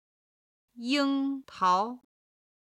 軽声の音は音源の都合上、四声にて編集しています。
樱桃　(yīng táo)　サクランボ
14-ying1tao2.mp3